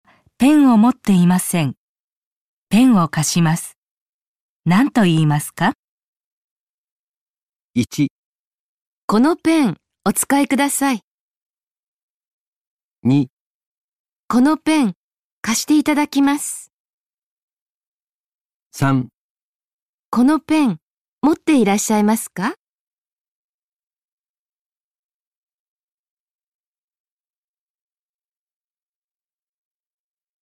問題３：このもんだいでは、まず質問を聞いてください。そのあと、問題用紙を見てください。読む時間があります。それからはなしを聞いて、問題用紙の1から4の中から、正しい答えを一つえらんでください。